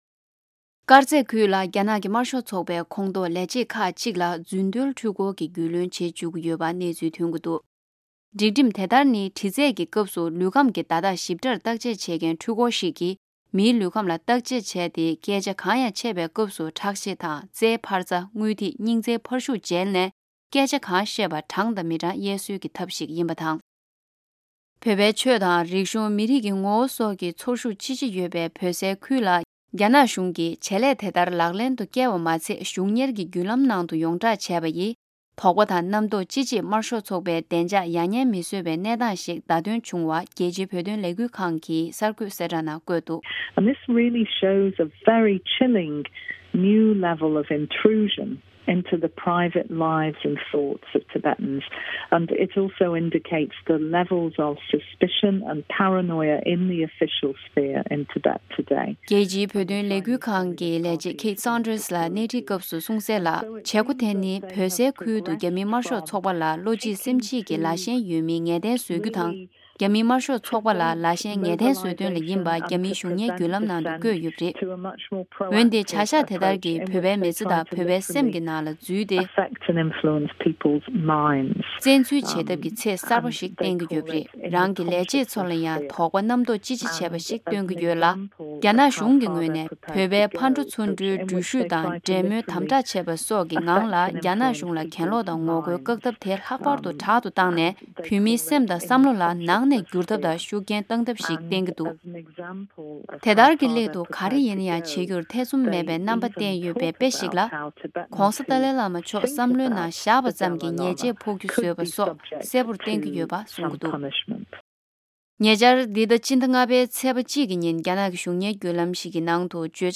གནས་འདྲི་ཞུས་པ་ཞིག